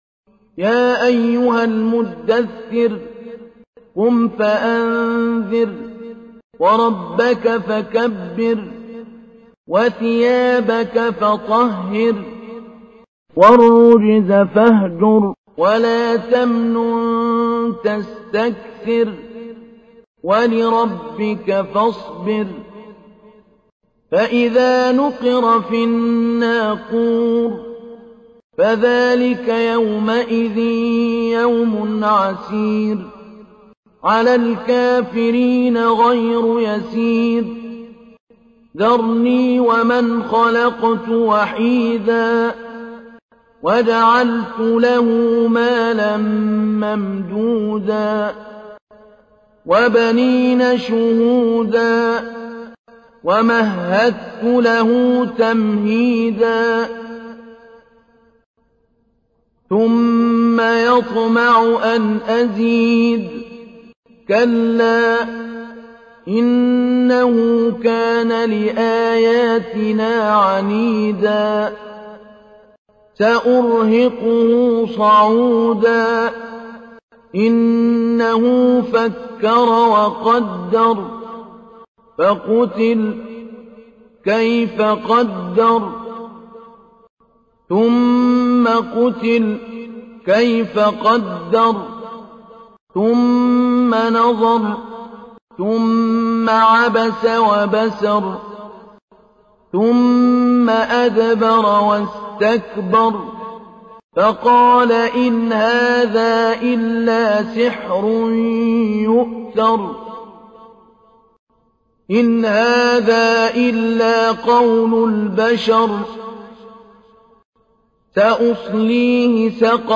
تحميل : 74. سورة المدثر / القارئ محمود علي البنا / القرآن الكريم / موقع يا حسين